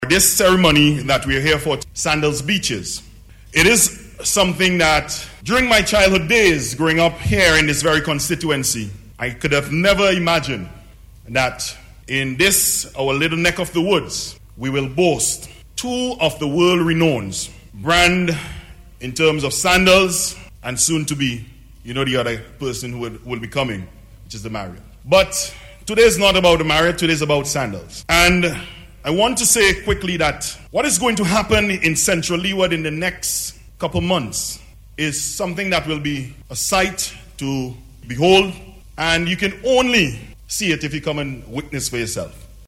He was speaking at yesterday’s signing ceremony for the construction of a Beaches Resort at Mt. Wynne/Peter’s Hope,